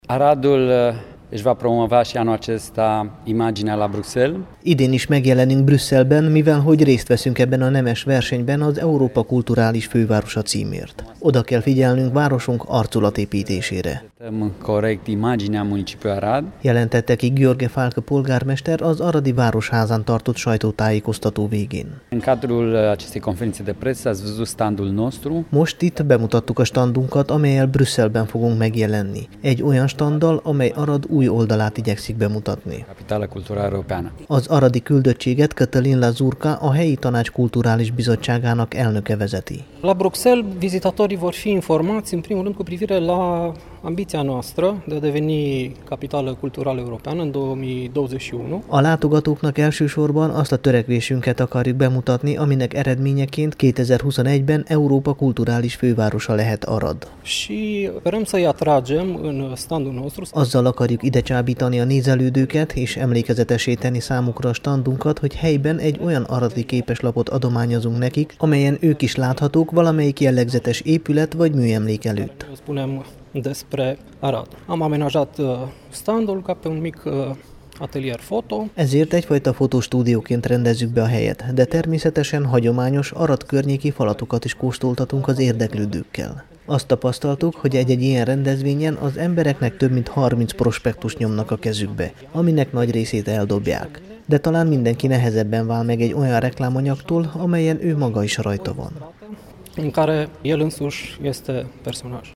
Hallgassa meg a Temesvári Rádió Európa hullámhosszán című euroregionális műsorában elhangzó összeállítást!